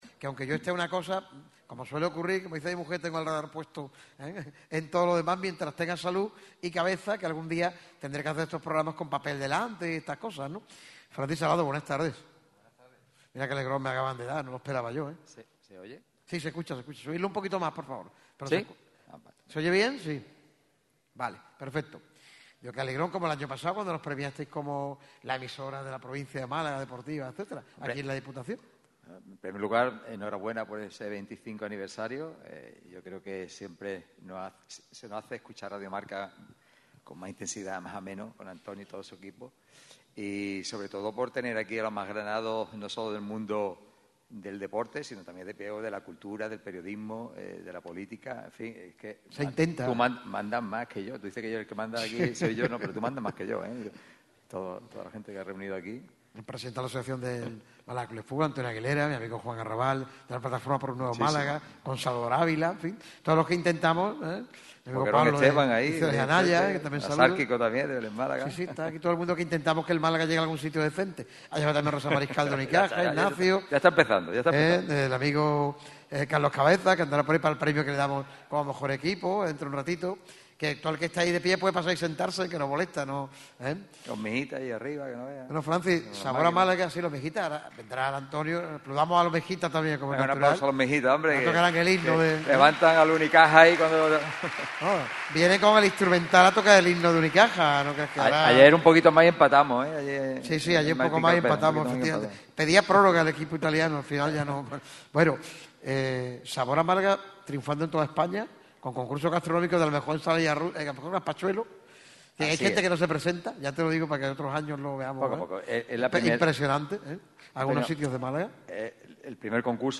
Por ello, el micrófono rojo lo celebrará junto a numerosos invitados y protagonistas de excepción en el ya habitual y tradicional escenario del Auditorio Edgar Neville de la Diputación de Málaga con el patrocinio de Mango TROPS. Una mañana de sorpresas, regalos y celebración en un evento que promete no dejar indiferente a nadie.